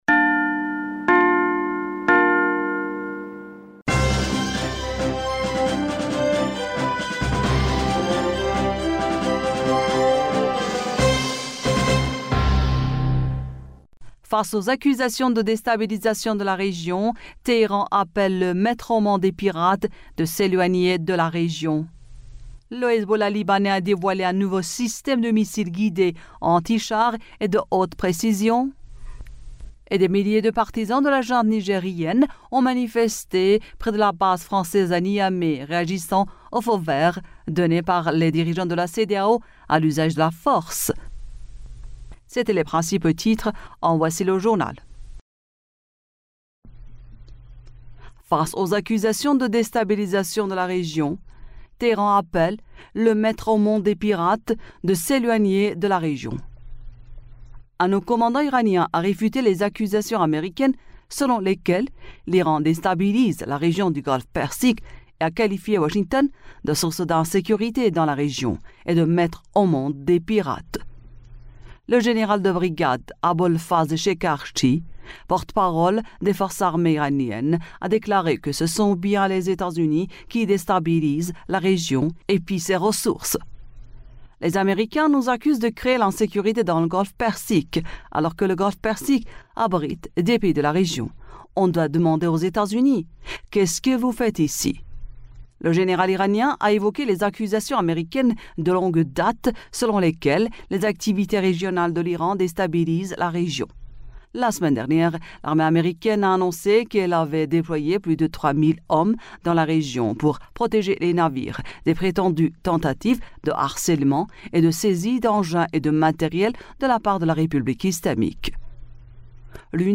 Bulletin d'information du 12 Aout 2023